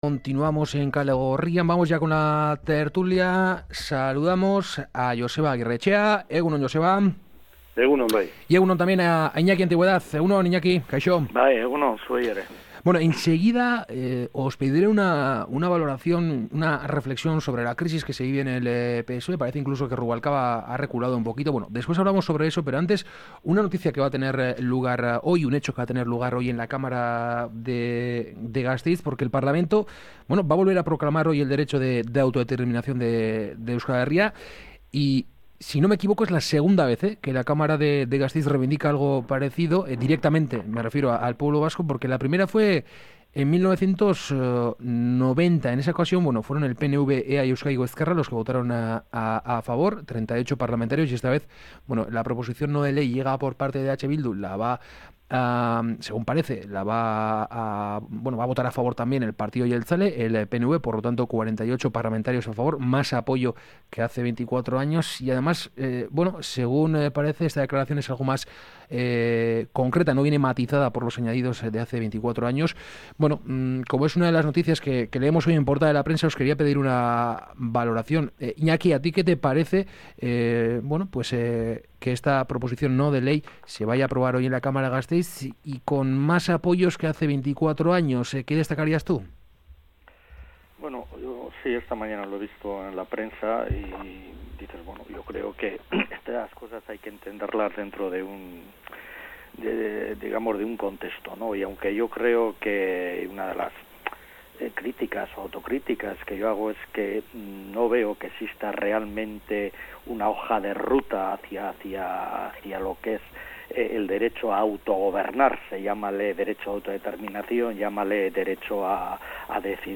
Charlamos y debatimos sobre algunas de las noticias mas comentadas de la semana con nuestros colaboradores habituales. Hoy, participan en la tertulia Iñaki Antigüedad y Joseba Agirretxea sobre la crisis que se vive en el PSOE y el derecho de autodeterminación.